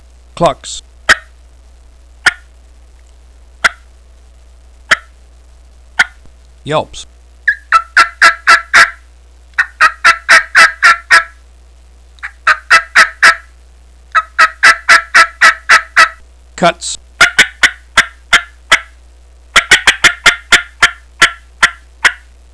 Yellow Yelper 3 Reed, 3 Cutt Mouth Call
Listen to 22 seconds of clucks, yelps, & cutts